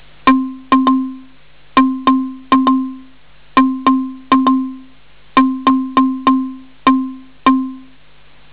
When you would listen to it, though, (e.g., the pattern being played from left to right, with every line being a 16th note and every dot a 16th rest) you would quickly hear a regular pattern -the beat-, and could probably easily tap your foot along with it.